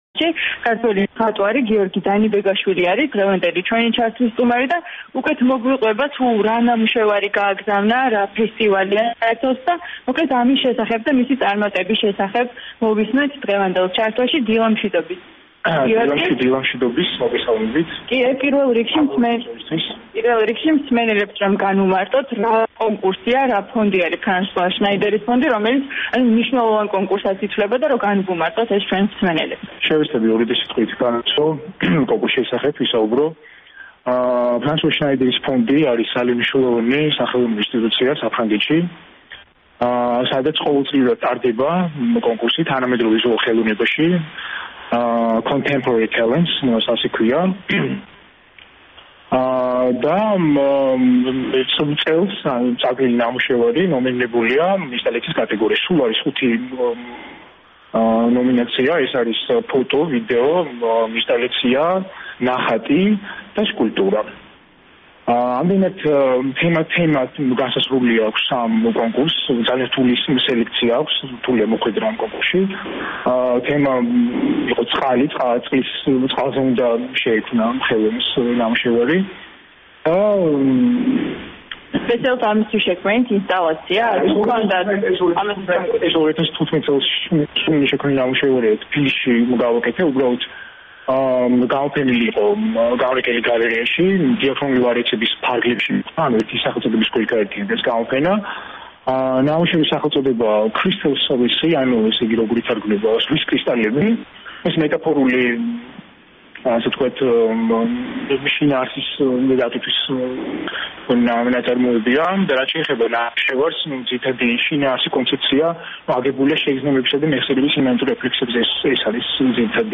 “თავისუფლების დილის” ჩართვაში